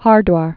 (härdwär, hûr-)